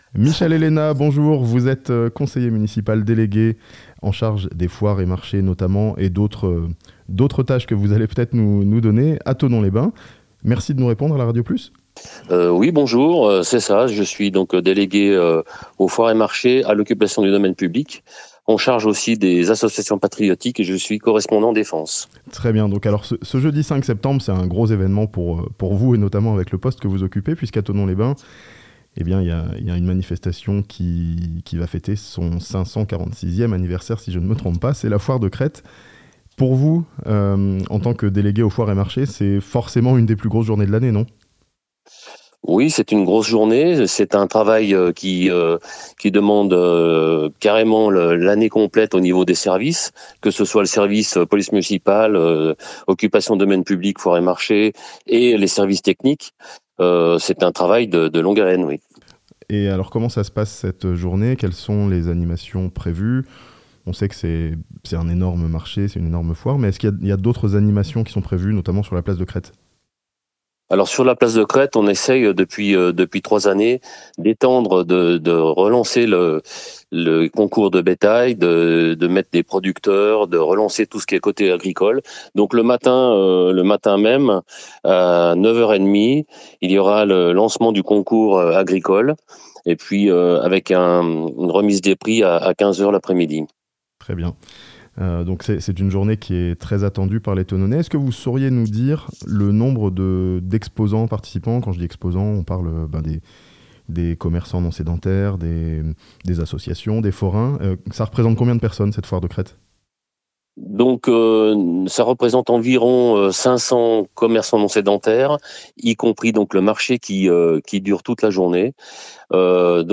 La 546ème foire de Crête a lieu ce jeudi à Thonon (interview)